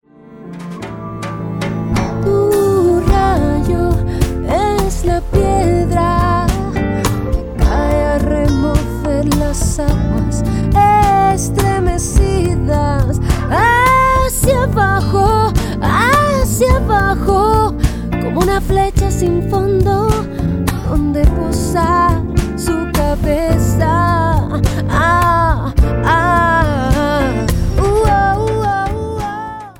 banda femenina
Música popular